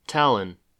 1. ^ /ˈtælɪn/
TAL-in, US also /ˈtɑːlɪn/ TAH-lin;[5] Estonian: [ˈtɑlʲːinː]
En-us-Tallinn.ogg.mp3